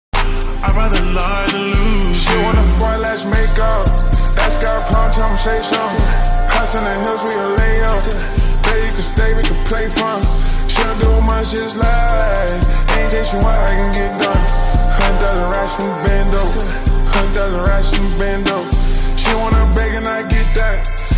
(slowed)